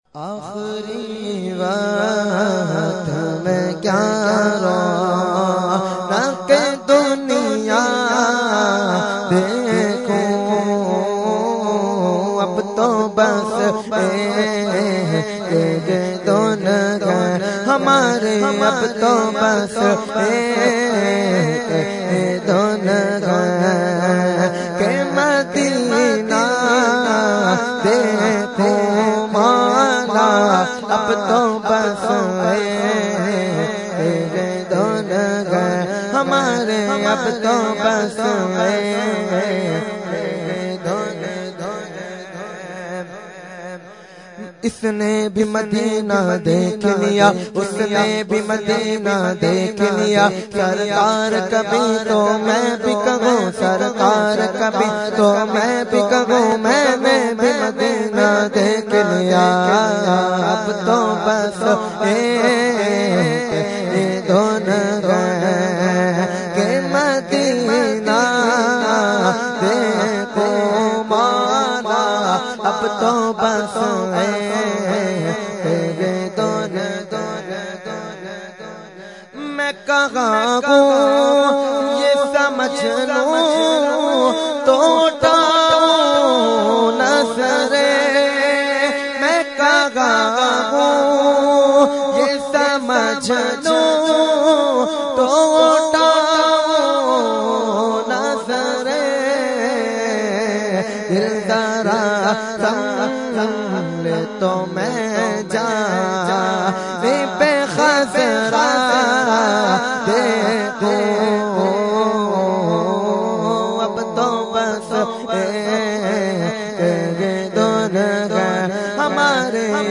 Category : Naat | Language : UrduEvent : 11veen Sharif Lali Qila Lawn 2015